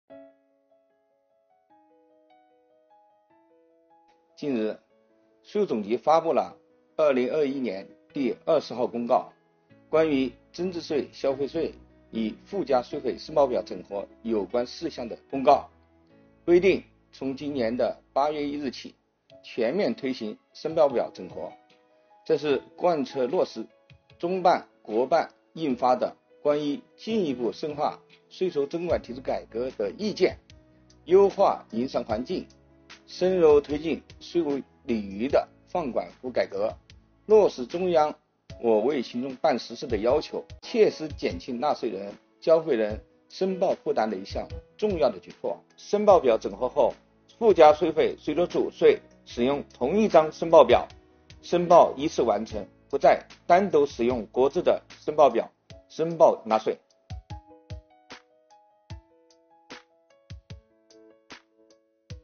国家税务总局推出最新一期“税务讲堂”网上公开课，税务总局货物和劳务税司副司长张卫详细解读增值税、消费税分别与附加税费申报表整合的背景意义、申报方法、主要变化和注意事项等，帮助纳税人缴费人更好了解政策、适用政策。